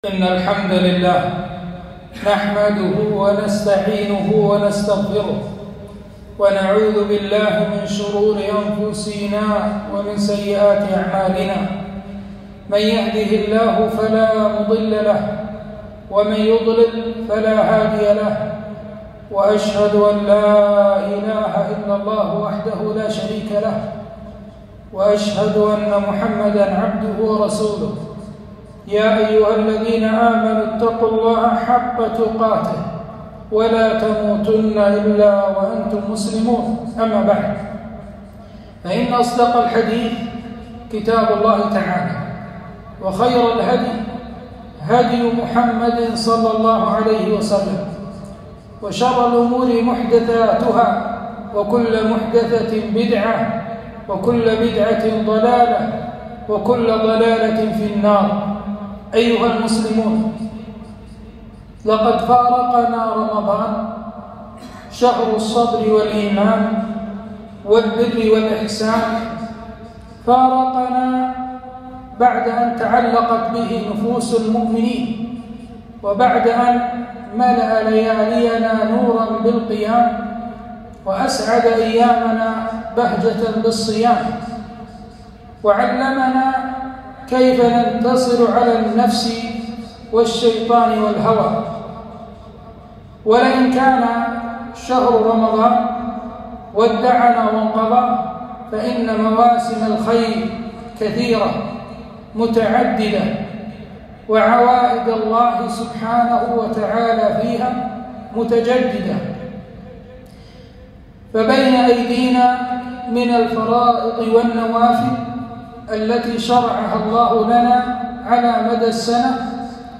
خطبة - ماذا بعد رمضان؟